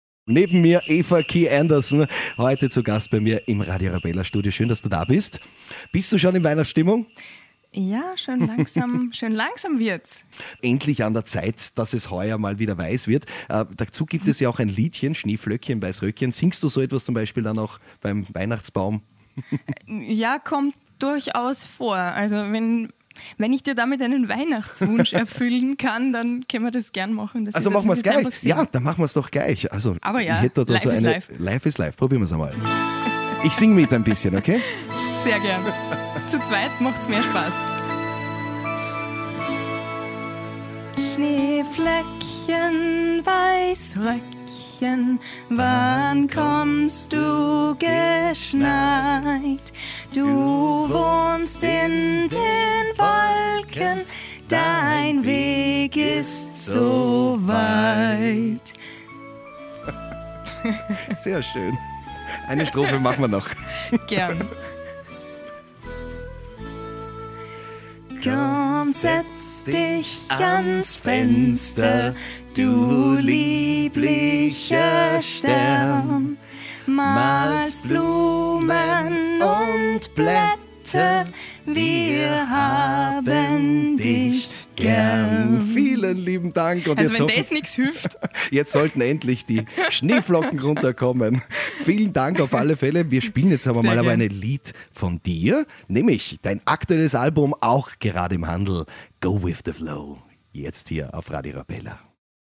ein bisschen Adventstimmung